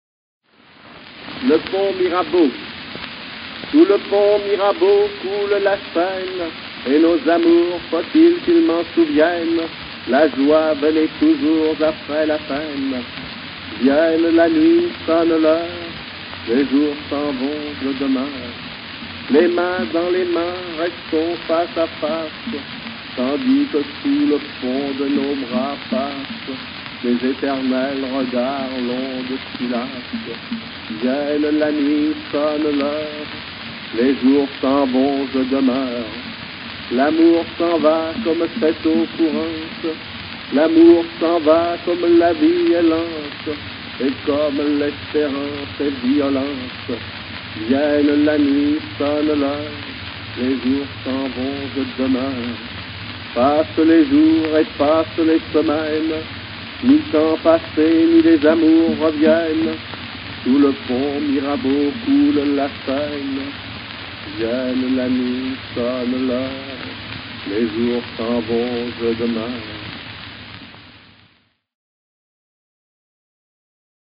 Le Pont Mirabeau read by Guillaume Apollinaire